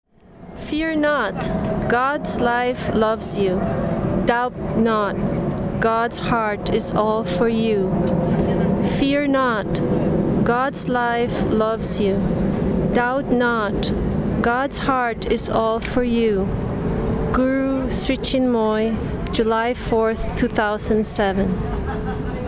reads the daily poem